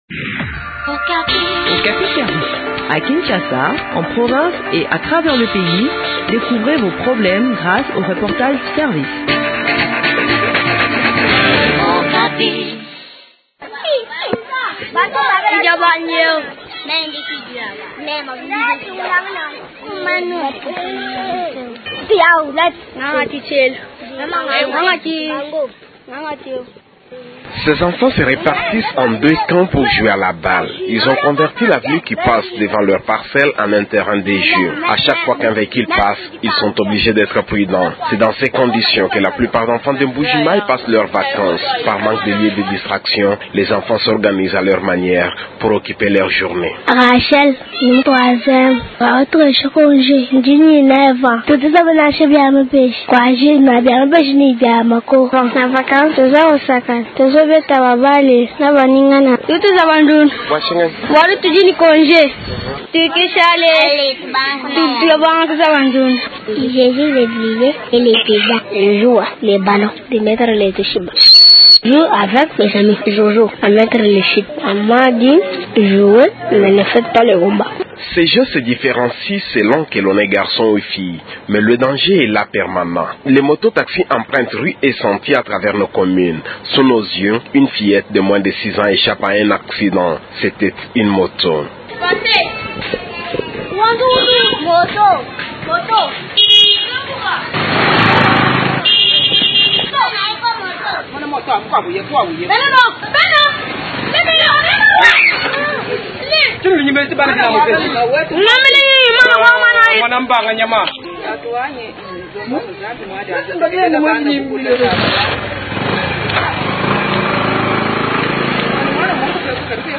expert en éducation.